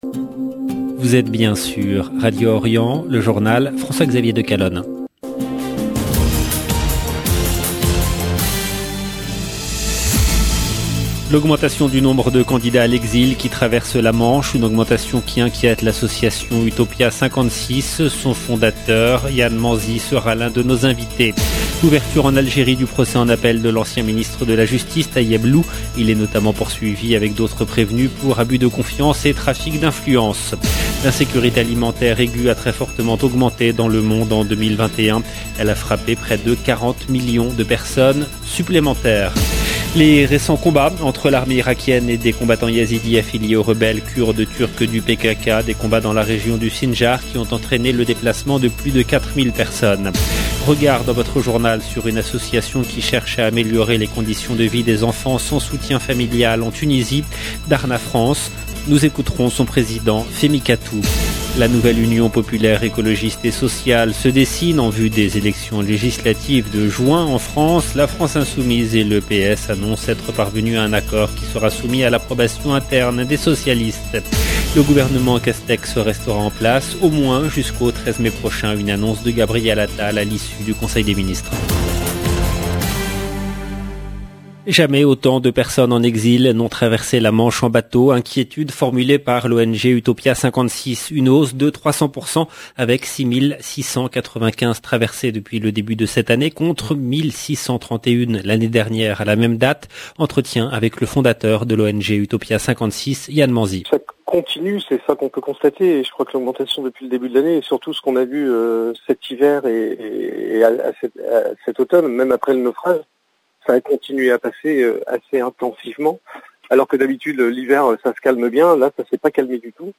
EDITION DU JOURNAL DU SOIR EN LANGUE FRANCAISE DU 4/5/2022